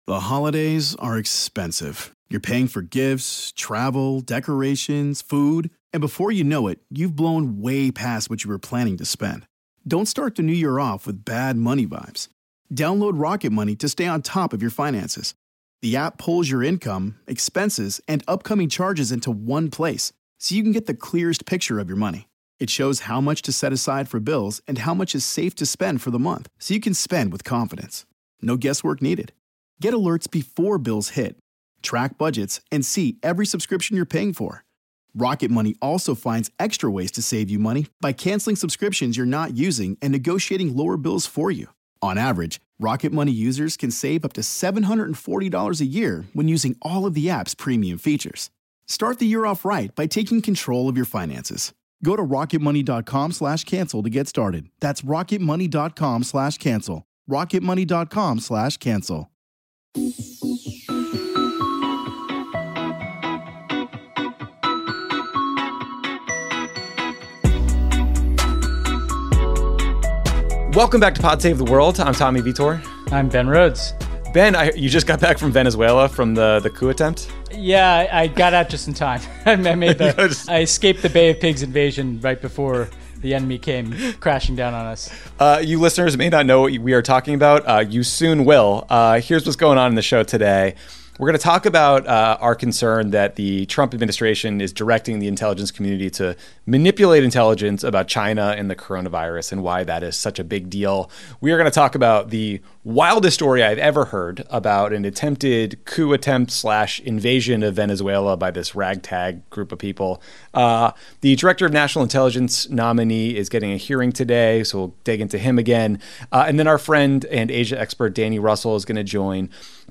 Then Asia expert Danny Russel joins to talk about White House plans to punish China over the coronavirus, how Beijing might respond, and what the hell we should make of Kim Jong Un’s reemergence after weeks of rumors about his health.